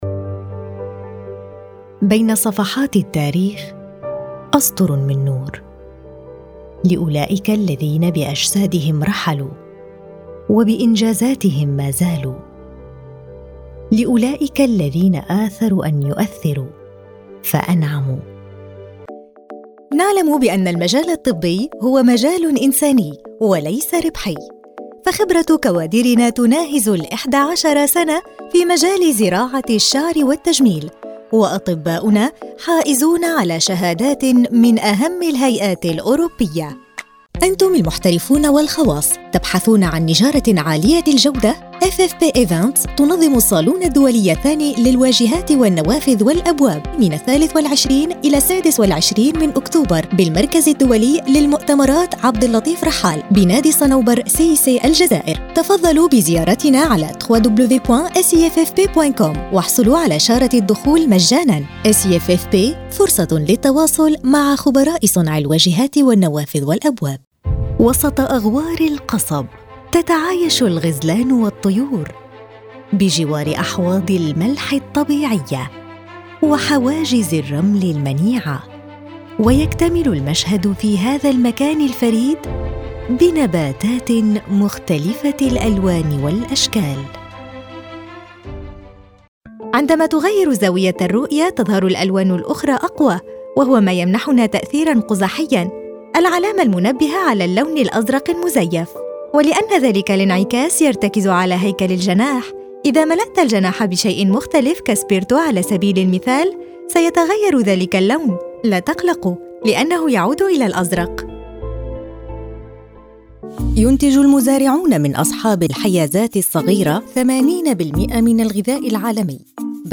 This setup ensures prompt access to her broadcast-quality booth and equipment, enabling her to deliver exceptional voice-over services efficiently.
She effortlessly transitions between serious and lighthearted tones, adapting her delivery to suit the subject matter.
Modern Standard Arabic Demo